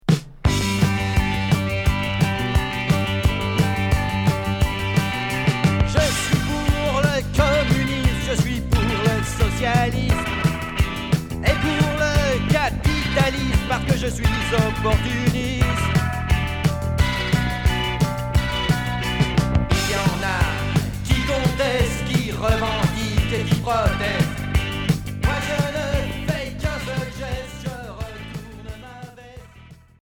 Rock Deuxième 45t retour à l'accueil